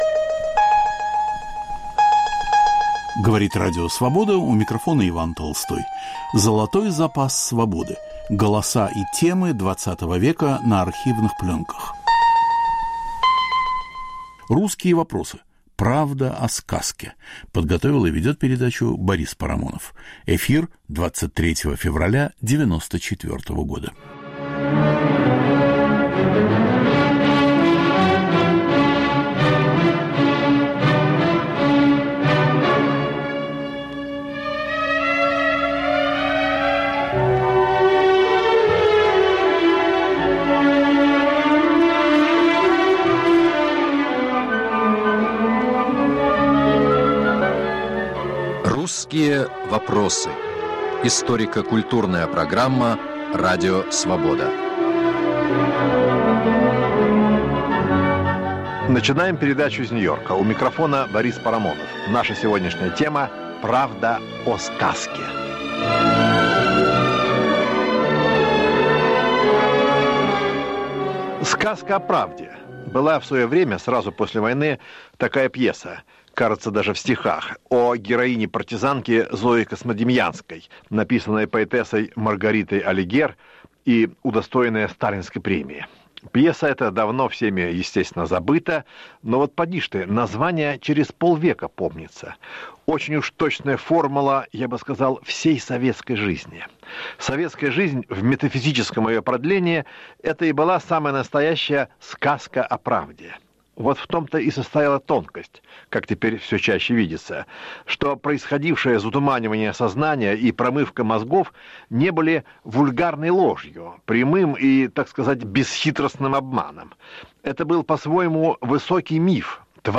Голоса и темы XX века на архивных пленках.